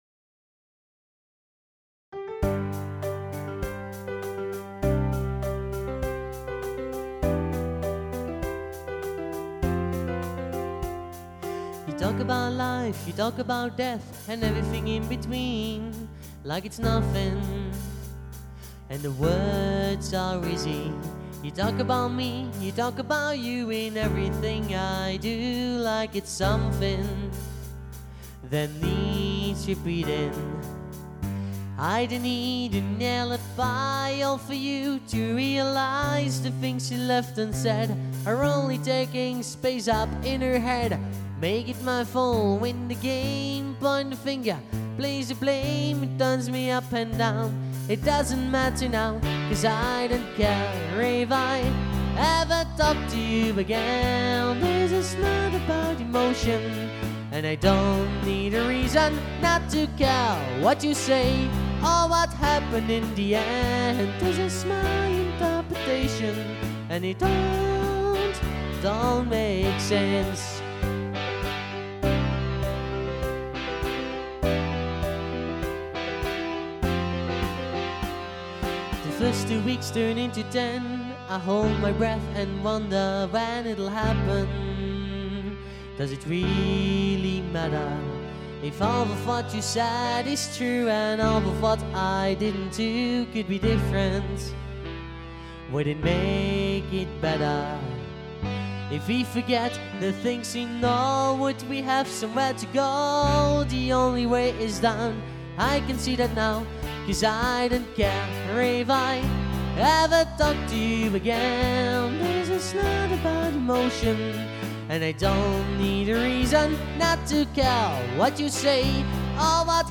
New Cover!
I covered a new number^^